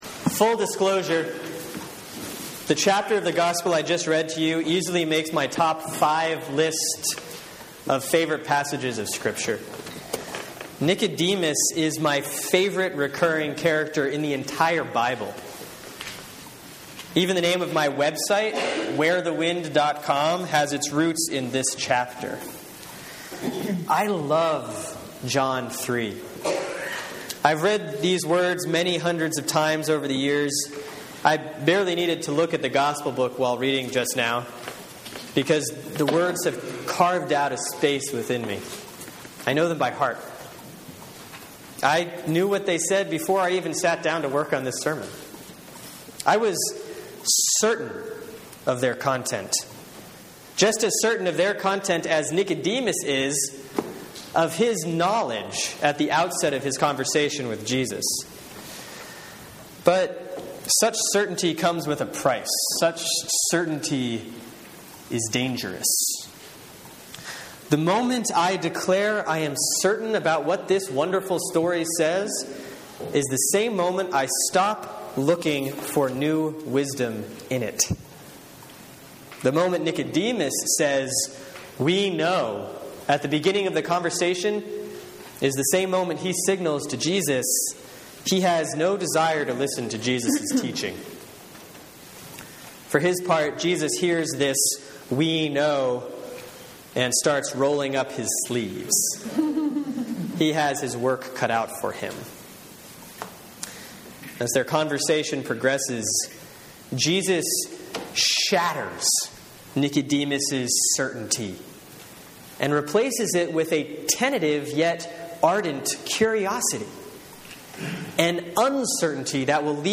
Sermon for Sunday, March 16, 2014 || Lent 2A || John 3:1-17